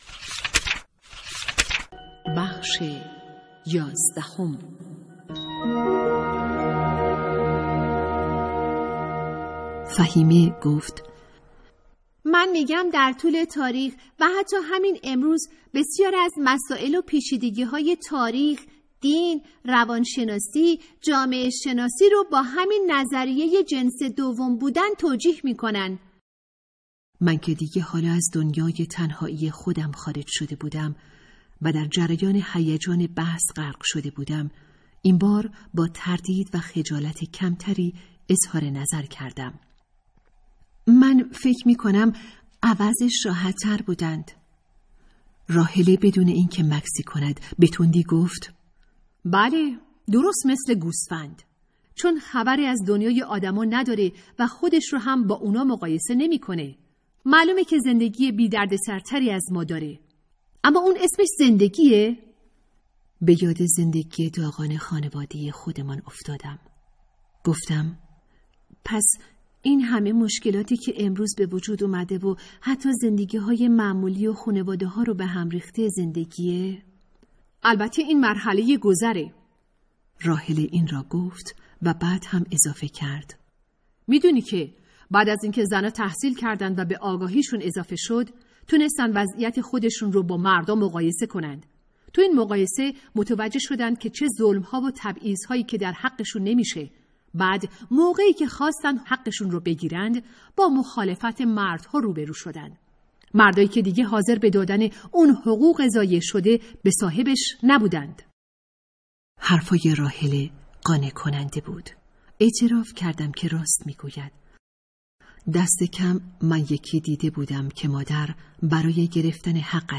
کتاب صوتی | دختران آفتاب (11)